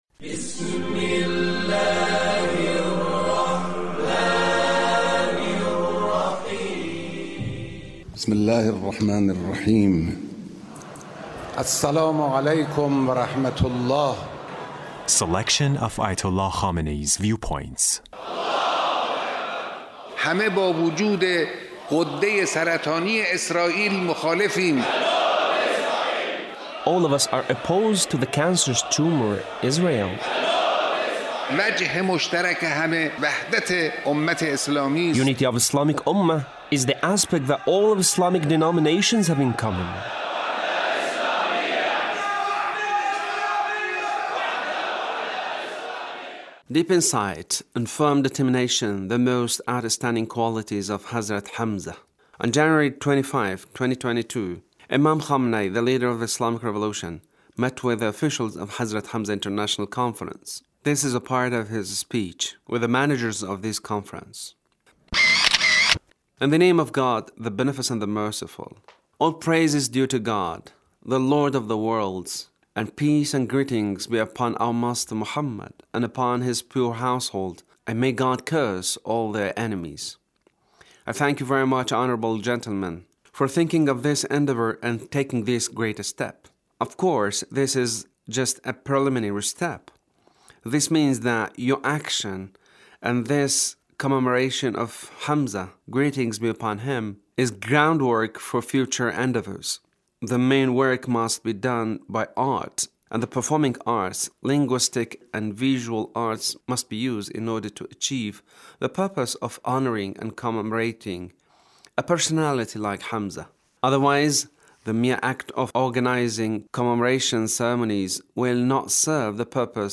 Leader's speech (1405)